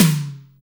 ODD TOM MID.wav